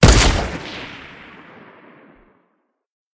mgun1.ogg